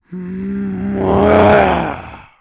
Male_Medium_Growl_02.wav